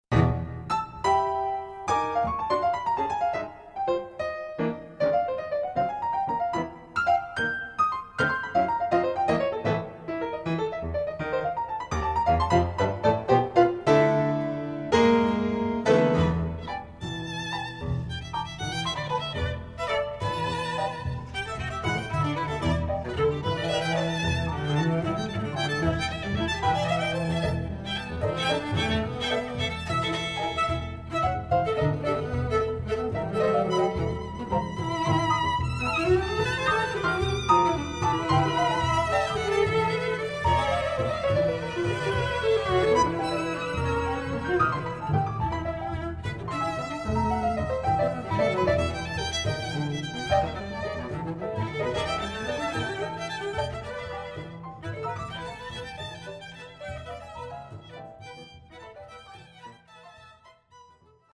Please note: These samples are not of CD quality.
Quintet for Piano and Strings